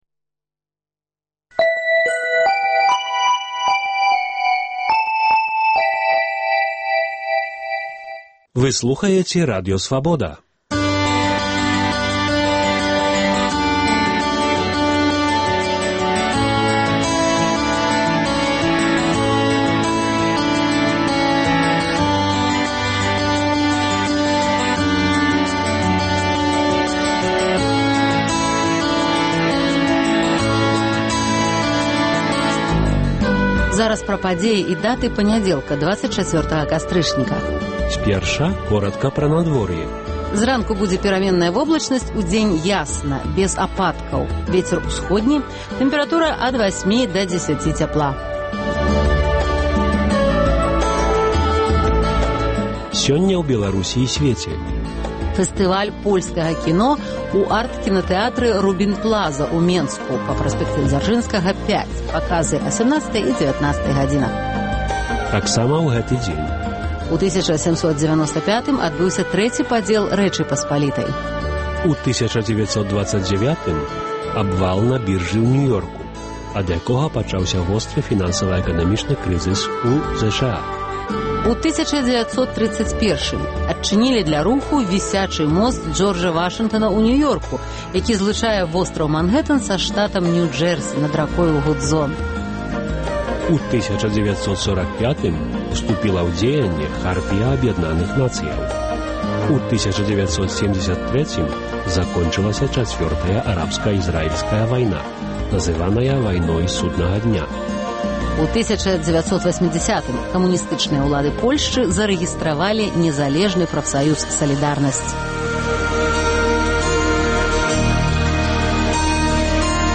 Ранішні жывы эфір